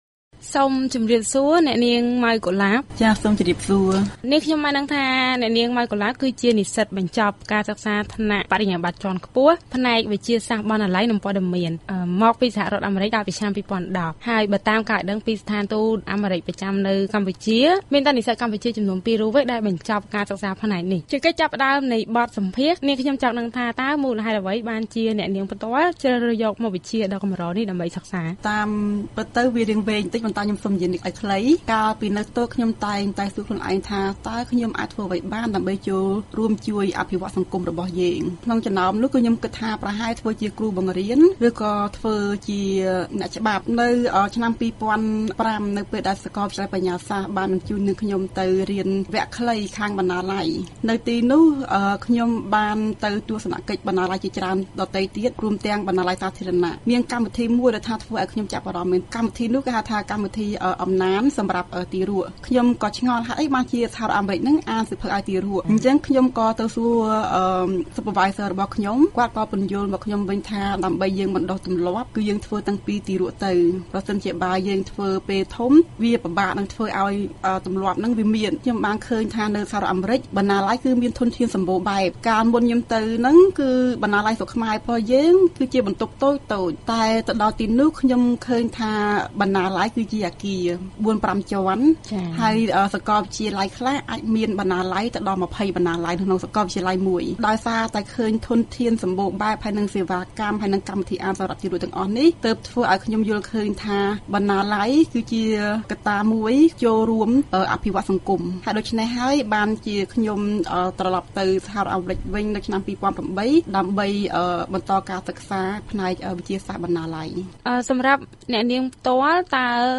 បទសម្ភាសន៍ VOA៖ ទម្លាប់អានសៀវភៅត្រូវបណ្តុះតាំងពីវ័យជាទារកទៅ